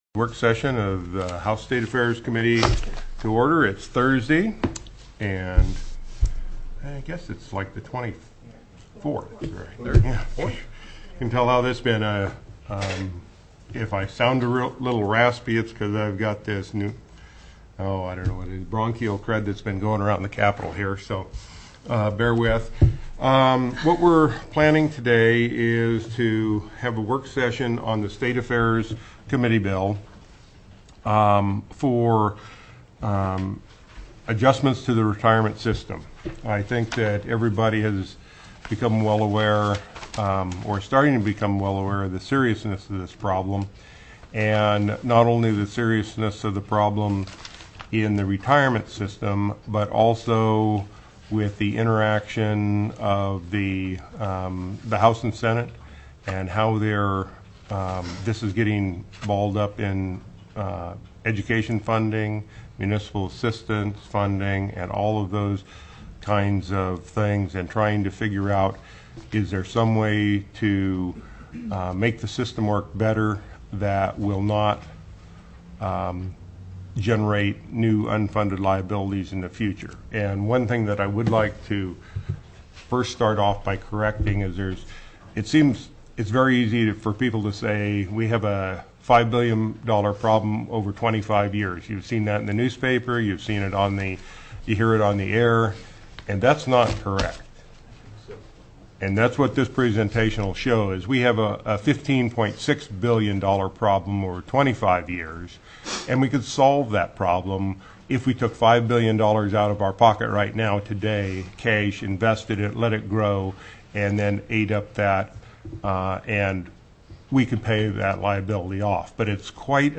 03/24/2005 08:00 AM House STATE AFFAIRS